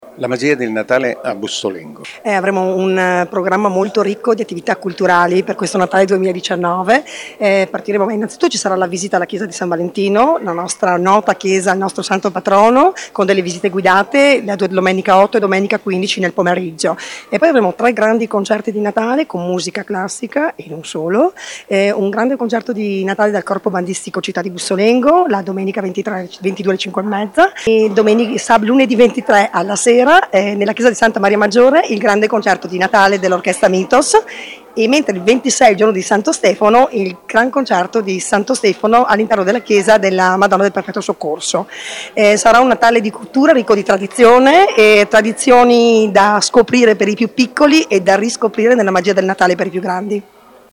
l’assessore alla Cultura Valeria Iaquinta
Valria-Iquinta-assessore-alla-cultura-comune-di-Bussolengo.mp3